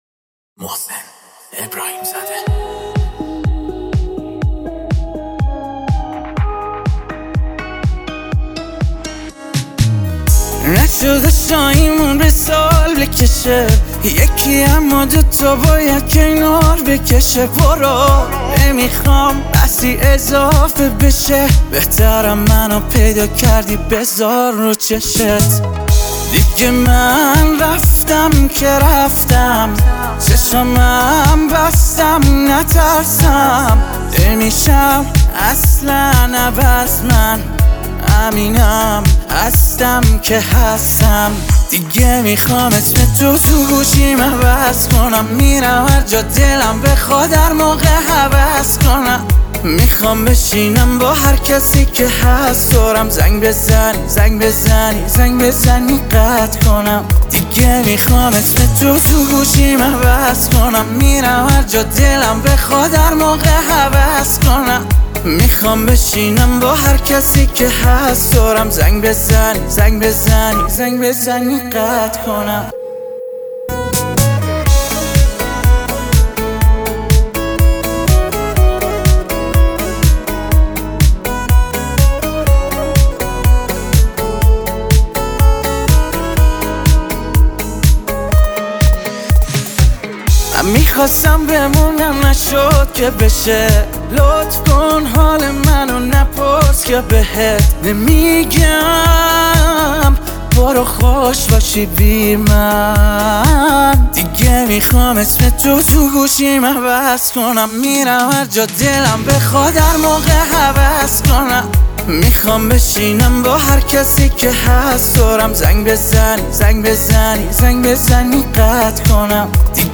ریتم شادی داره